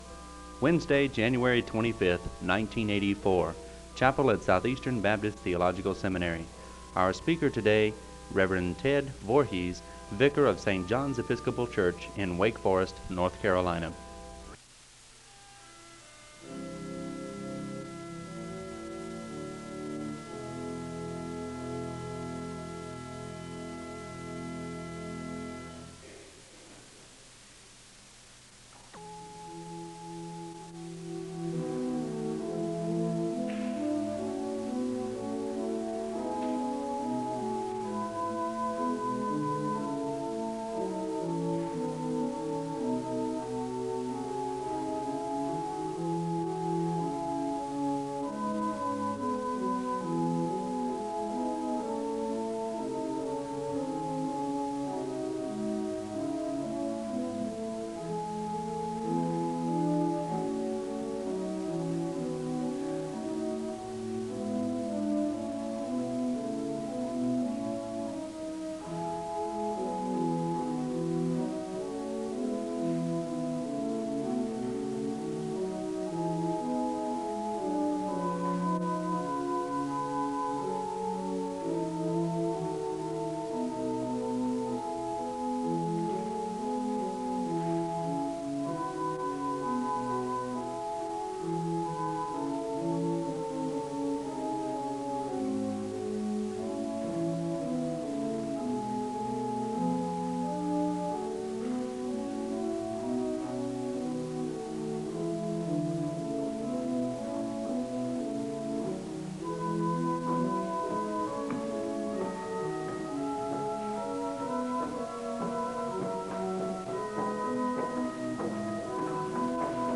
The service begins with organ music (00:00-03:25). The speaker gives a word of prayer (03:26-04:19).
A song of worship is performed (24:08-30:16).
SEBTS Chapel and Special Event Recordings SEBTS Chapel and Special Event Recordings